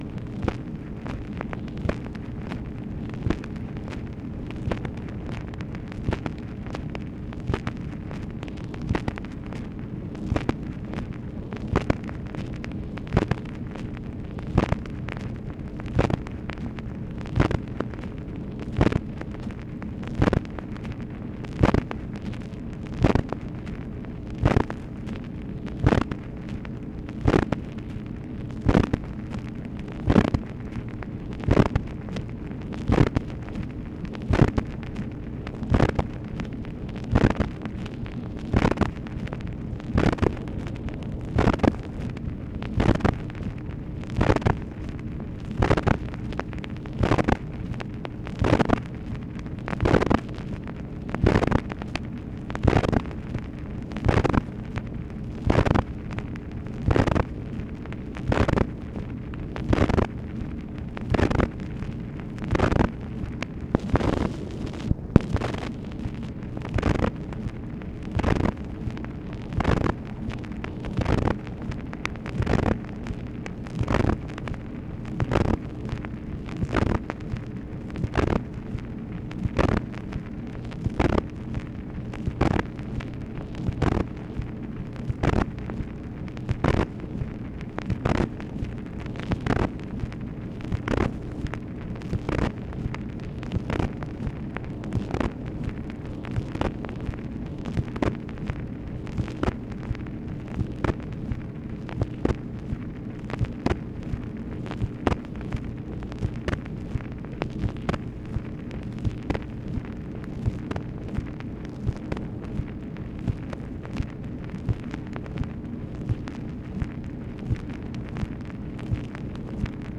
MACHINE NOISE, October 10, 1964
Secret White House Tapes | Lyndon B. Johnson Presidency